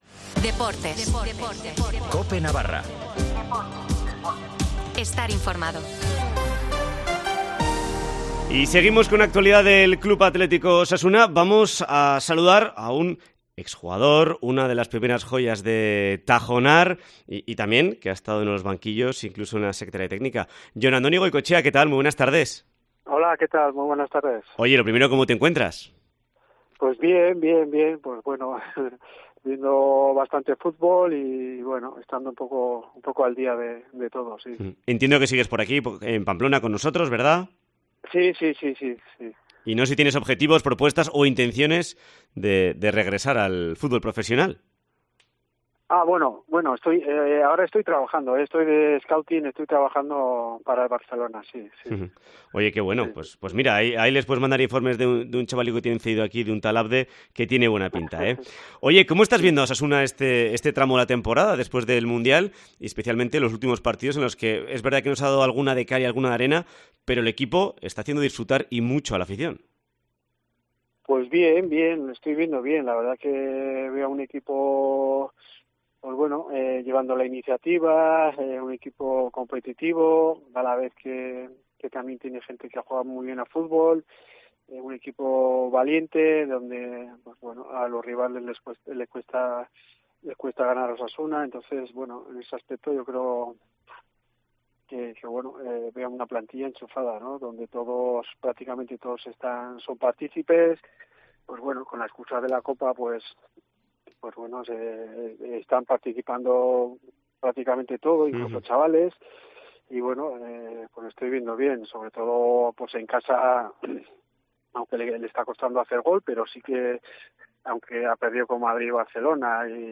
Entrevista con Andoni Goikoetxea para analizar la actualidad de Osasuna